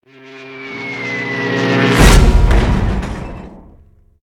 AA_drop_boat.ogg